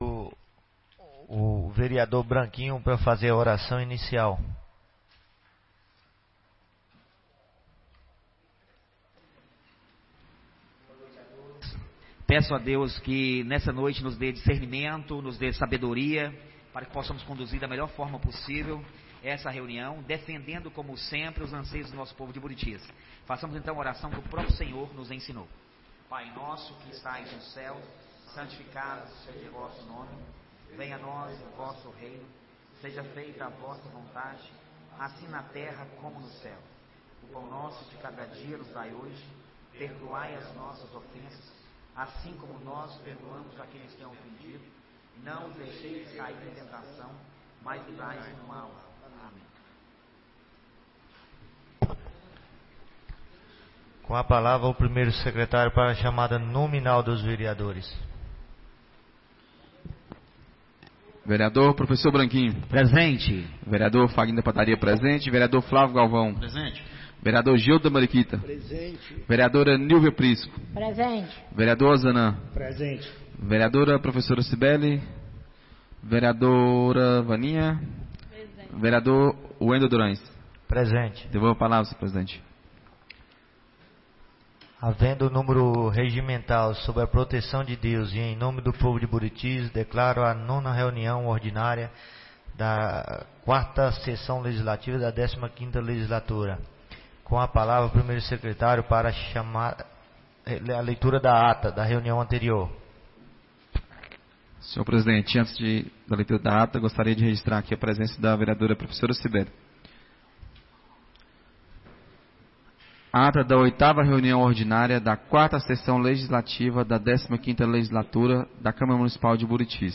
9ª Reunião Ordinária da 4ª Sessão Legislativa da 15ª Legislatura - 01-04-24